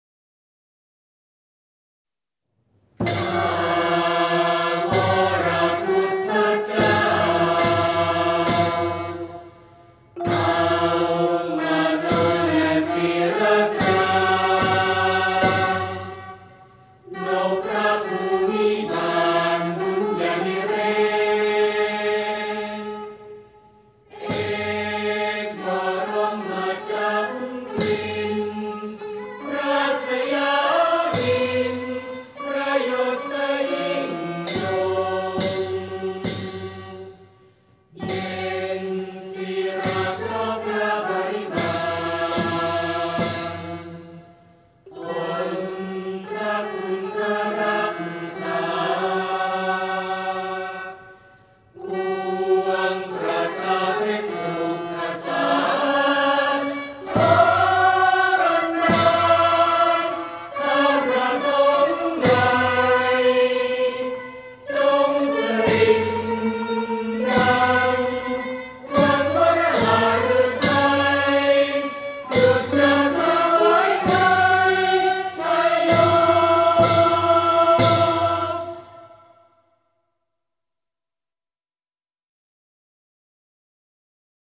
เพลงสรรเสริญพระบารมี (วงปี่พาทย์-ไม้นวม)